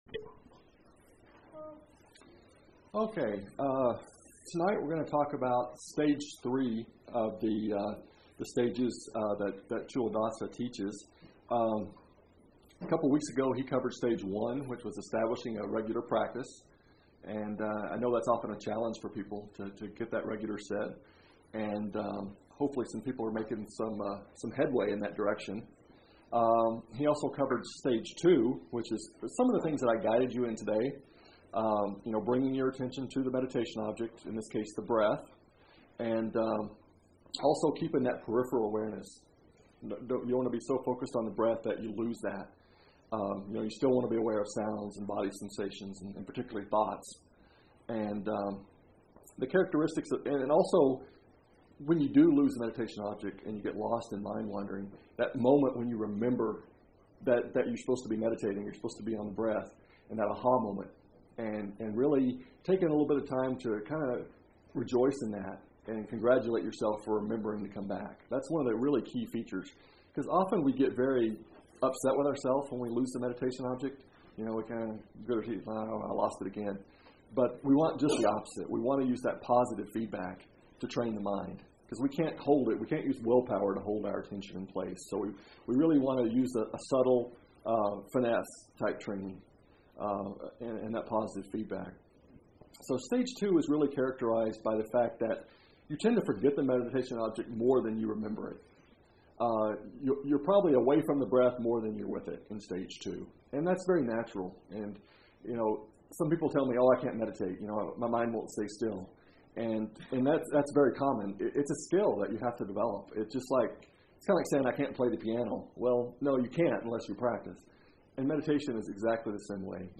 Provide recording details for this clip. This audio does not have a cleaned file yet, you are listening to the original.